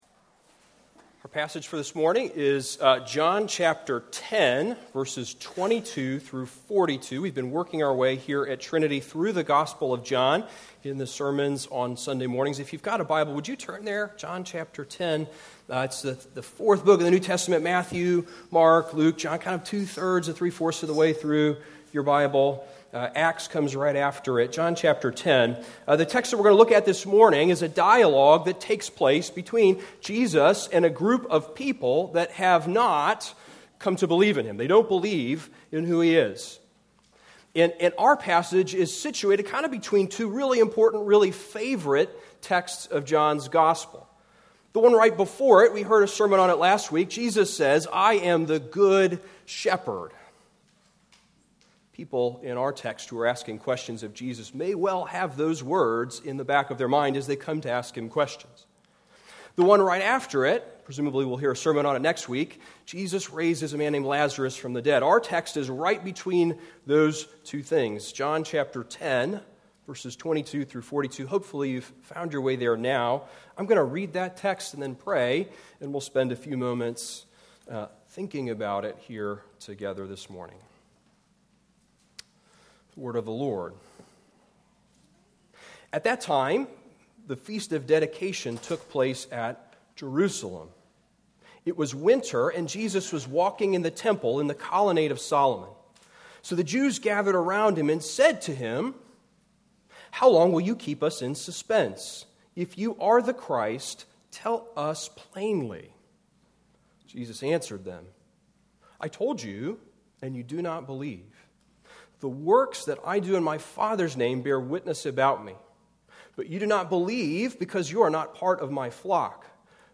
Hebrews 12:1-3 Service Type: Weekly Sunday